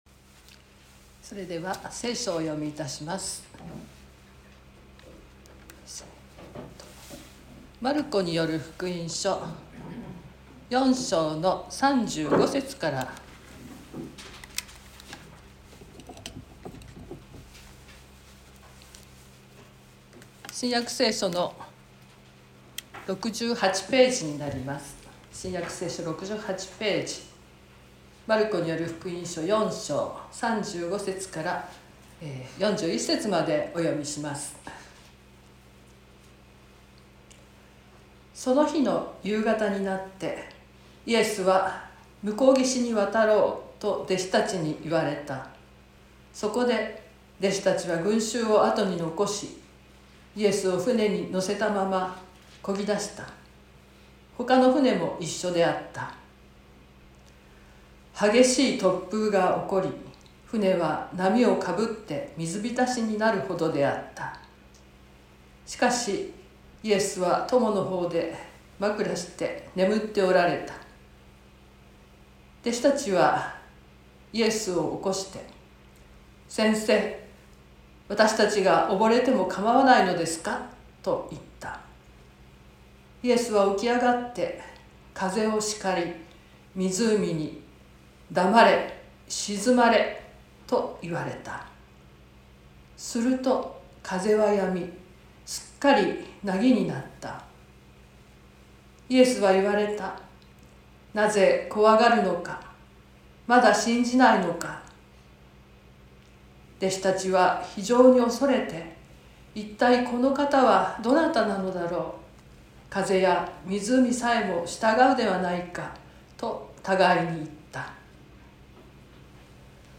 説教アーカイブ。
Youtubeで直接視聴する 音声ファイル 礼拝説教を録音した音声ファイルを公開しています。